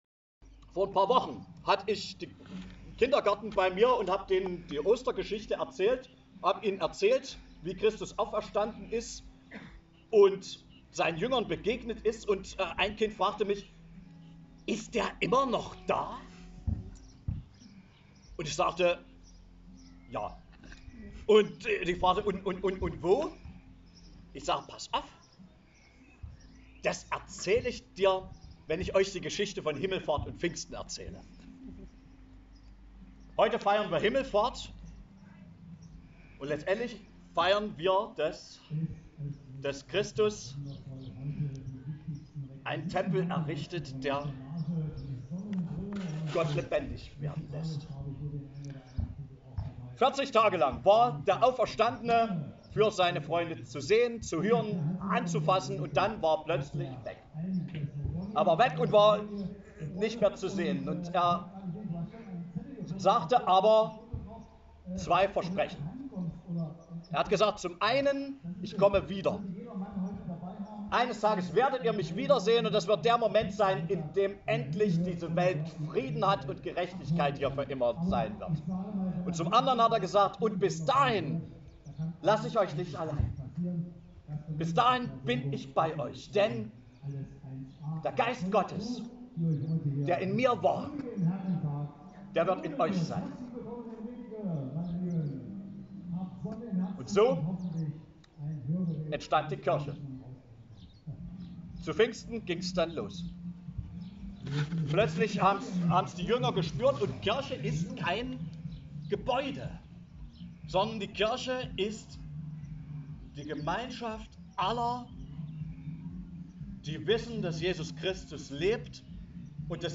Das wissen auch die Gläubigen und so waren immerhin 66 Menschen auf die Festwiese nach Rädigke gekommen.